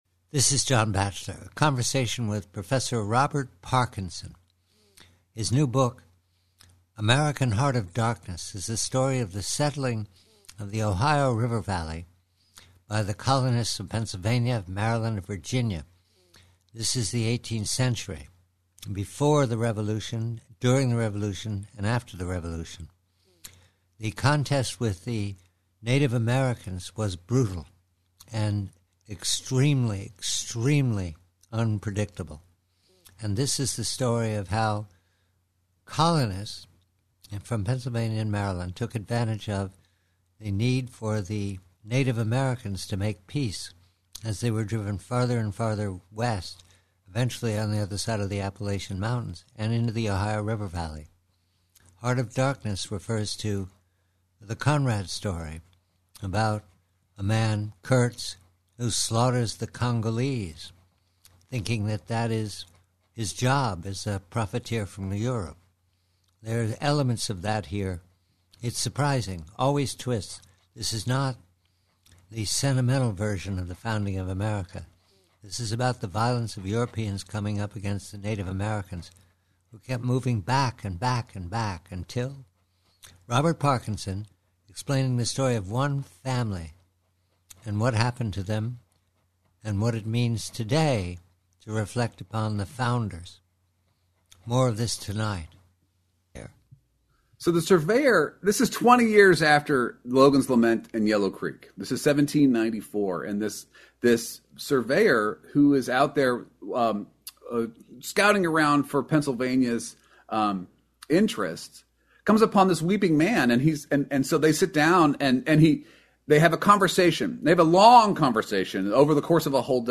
Conversation with Professor Robert Kagan